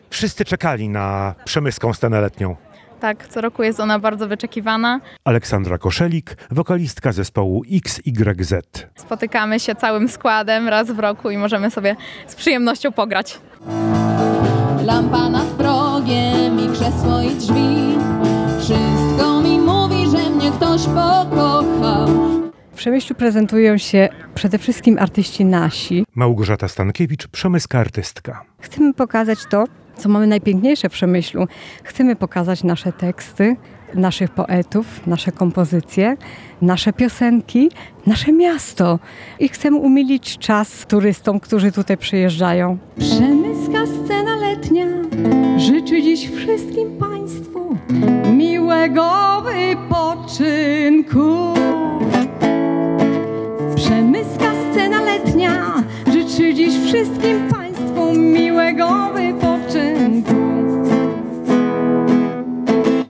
Po rocznej przerwie wystartowała Przemyska Scena Letnia. Cykl koncertów na Rynku zainaugurował zespół XYZ, który tworzą absolwenci i uczniowie Zespołu Szkół Muzycznych w Przemyślu.
Relacja